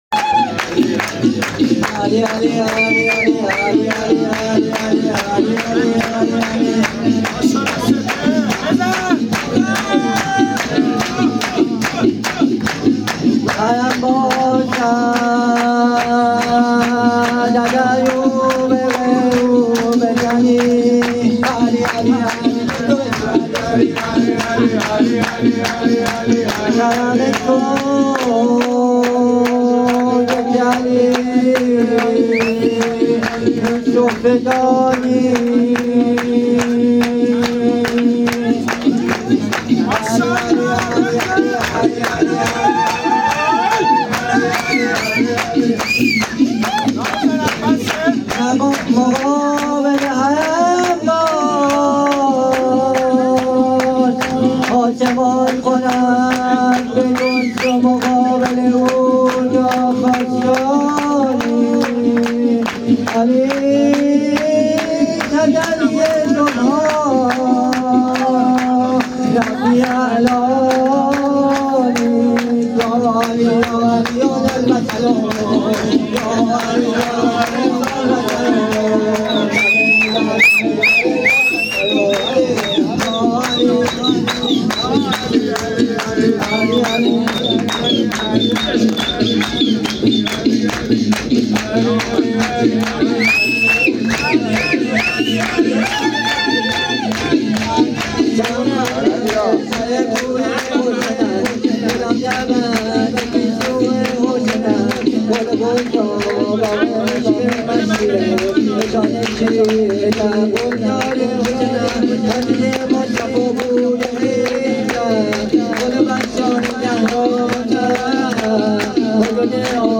مولودی خوانی
هیئت رقیه جان